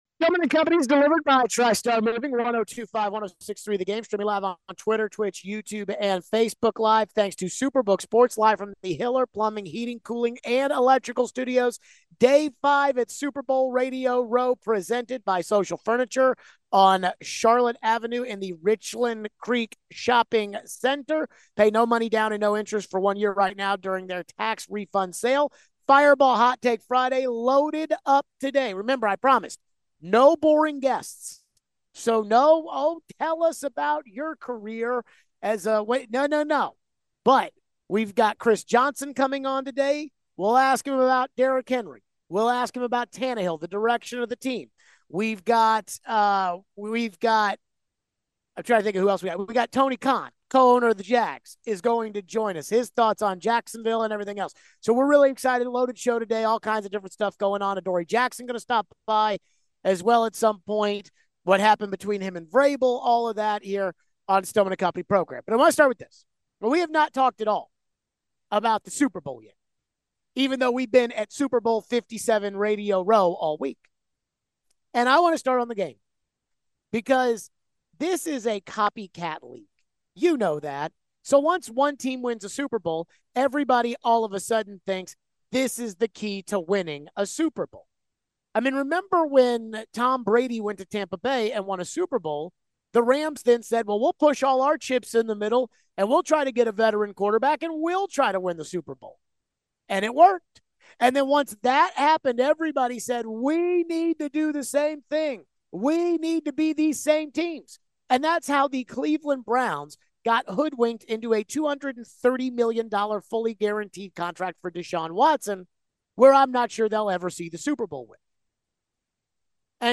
one last day from Radio Row at the Super Bowl. What do we think about the match-up and what it means for the NFL copycat league. How much would a second Super Bowl ring mean for Mahomes and his legacy? We give some thoughts on the Hall of Fame class.